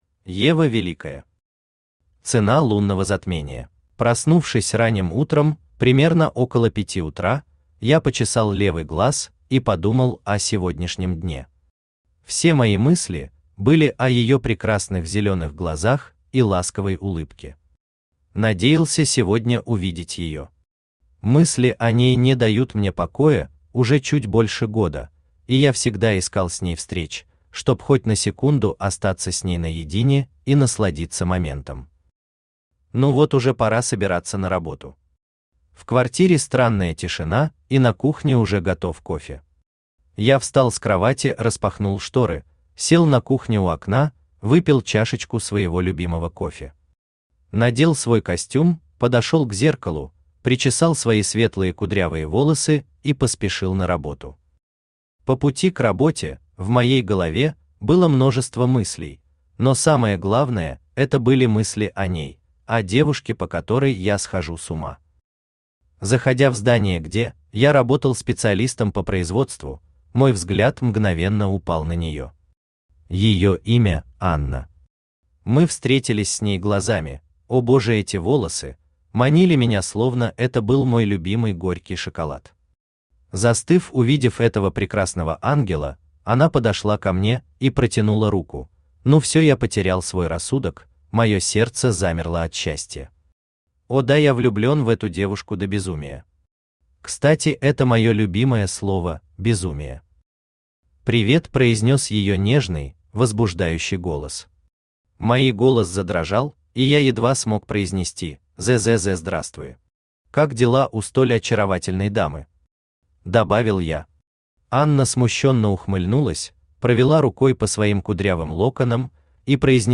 Аудиокнига Цена лунного затмения | Библиотека аудиокниг
Aудиокнига Цена лунного затмения Автор Ева Великая Читает аудиокнигу Авточтец ЛитРес.